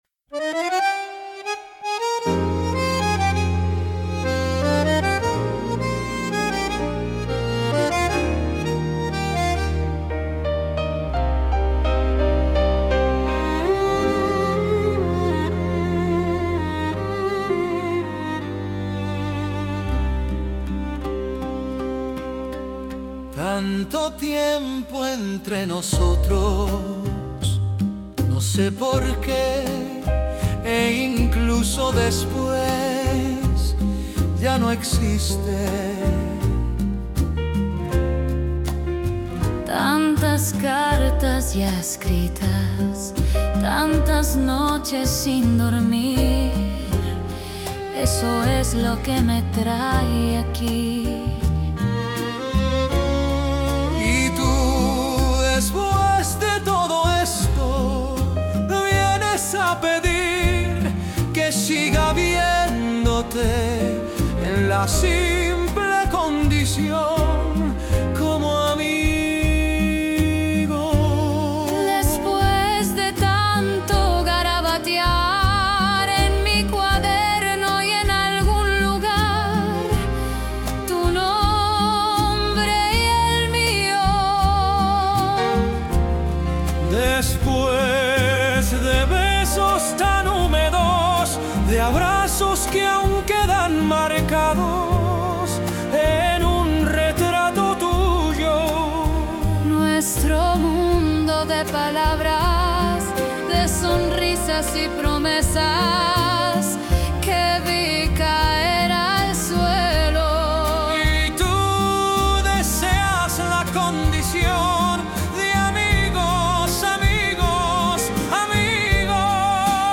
MÚSICA E ARRANJO E VOZ: IA